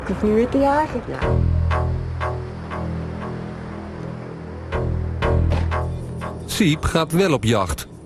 Category: Television   Right: Personal